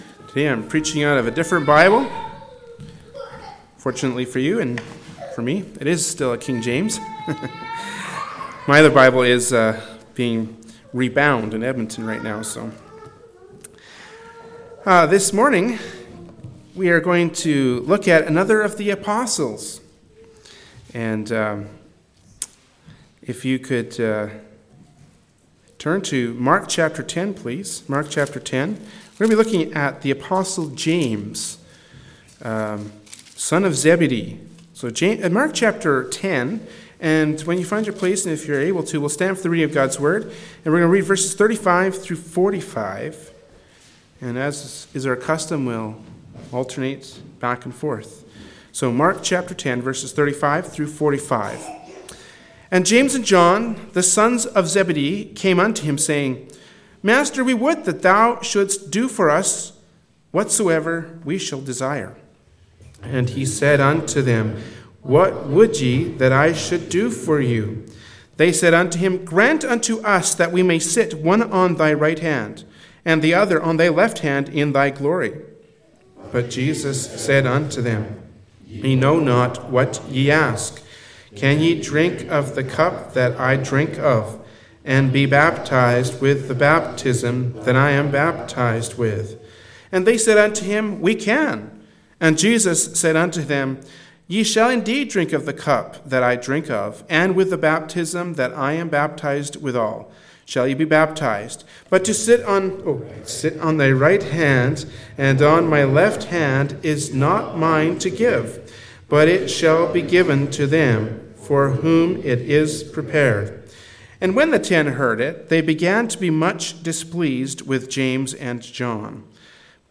Service Type: Sunday Morning Worship Service Topics: The Disciple James